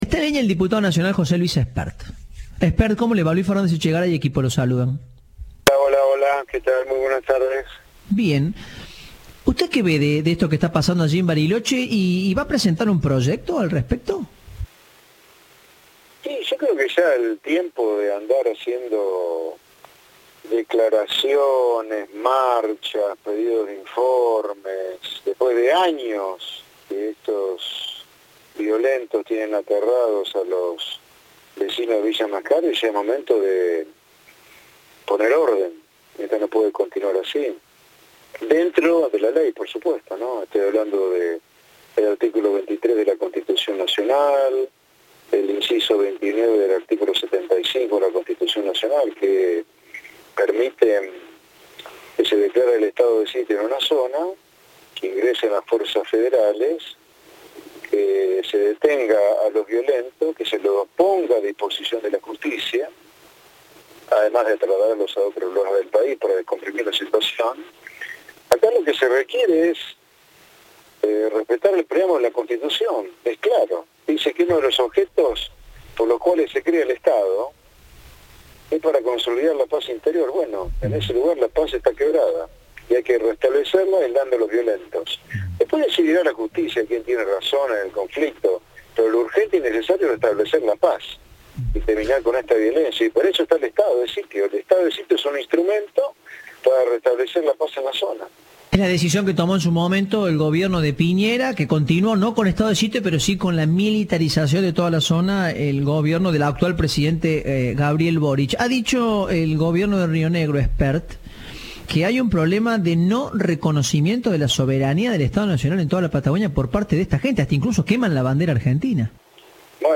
El diputado nacional habló con Cadena 3 sobre los ataques mapuches en la localidad rionegrina y anticipó que presentará un proyecto para que se declare el estado de sitio.